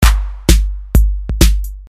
Tag: 130 bpm Electronic Loops Drum Loops 318.07 KB wav Key : Unknown